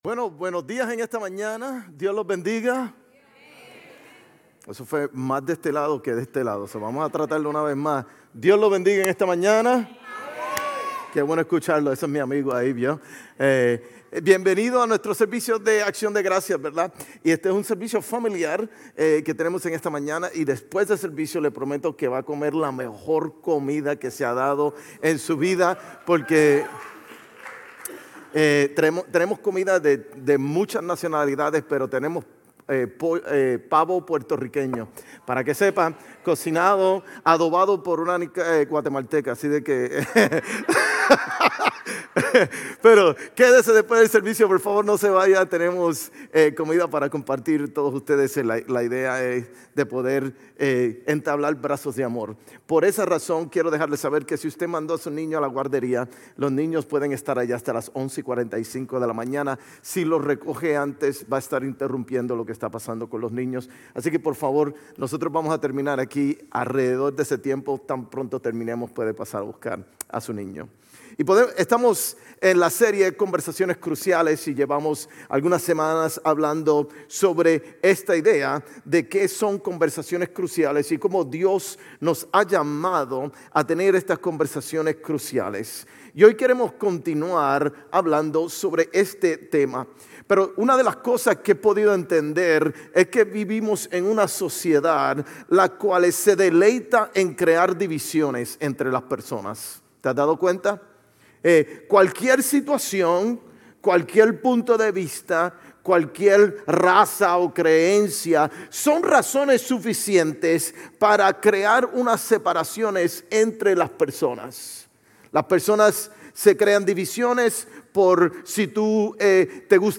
GCC-GE-November-5-Sermon.mp3